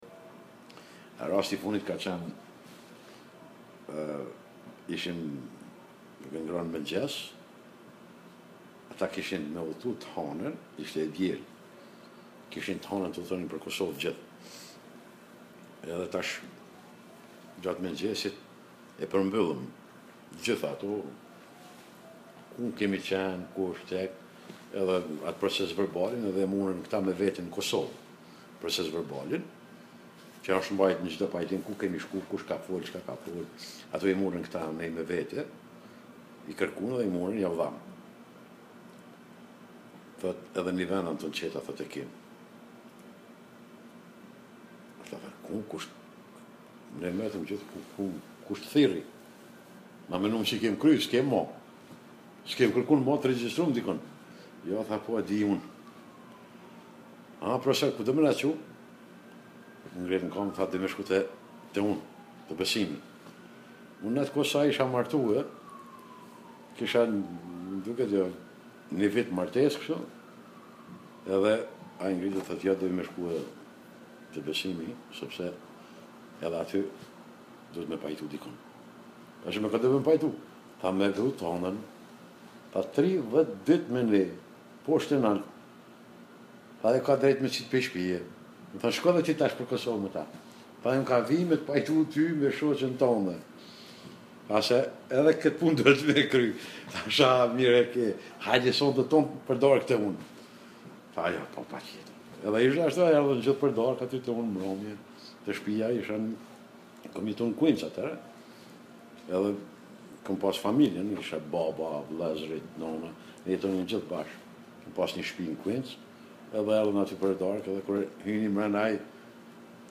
The audio interview section